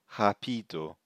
They show which part of the word is stressed and how to pronounce certain vowels. For example, á, é, and ó have a strong, open sound.